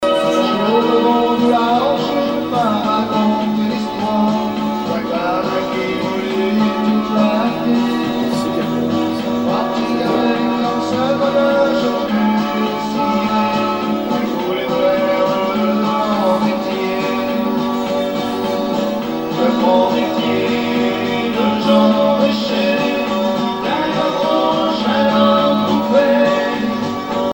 Chant maritime
repiquage d'une cassette
Informateur(s) Club d'anciens de Saint-Pierre association
Genre strophique
Pièce musicale inédite